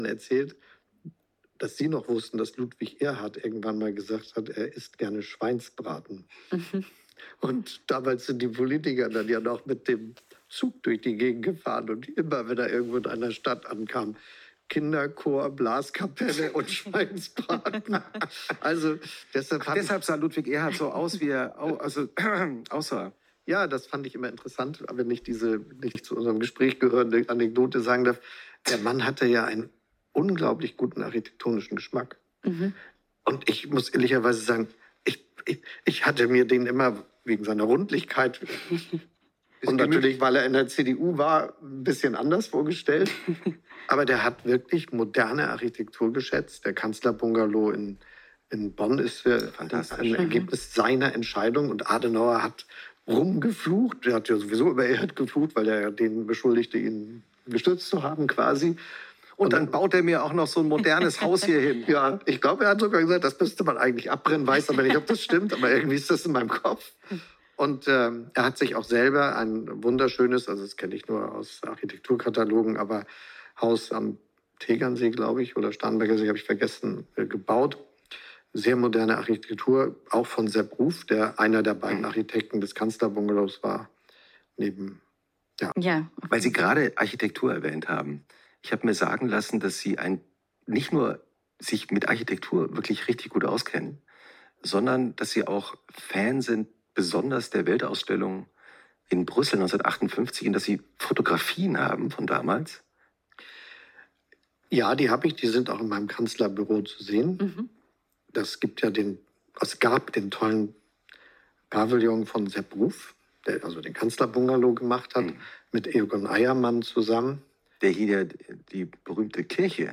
Bundeskanzler Olaf Scholz zu Gast im Wochenendpodcast, ZEIT ONLINE